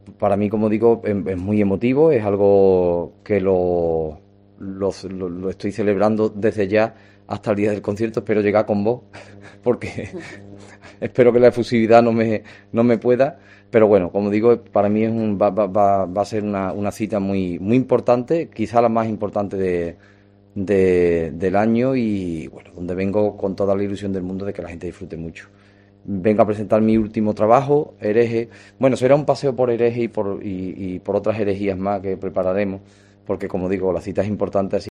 Arcángel, cantaor flamenco